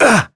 Crow-Vox_Damage_01.wav